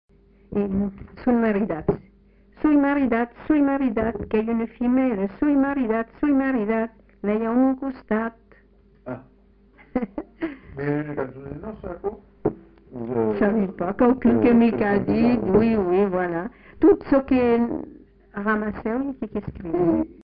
Aire culturelle : Bazadais
Lieu : Captieux
Genre : chant
Effectif : 1
Type de voix : voix de femme
Production du son : chanté